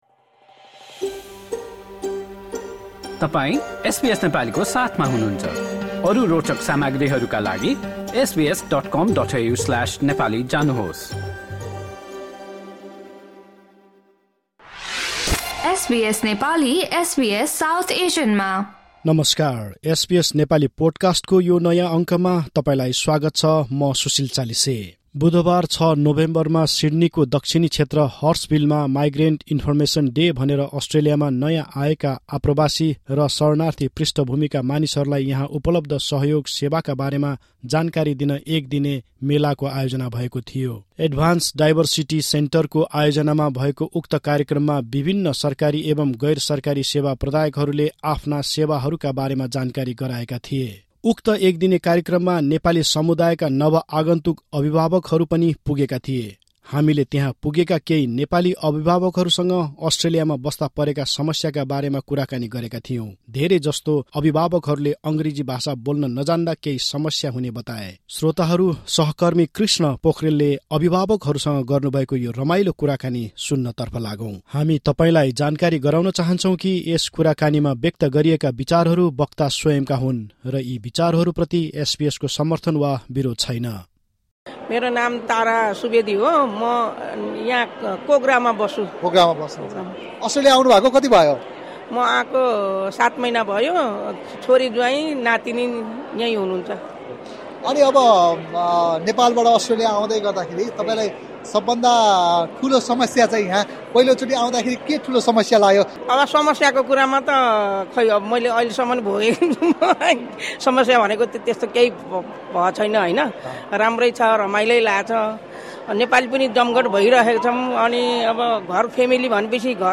On Wednesday, 4 November, Migrant Information Day took place in Sydney’s Hurstville to inform newly arrived migrants and refugees about available support services in Australia.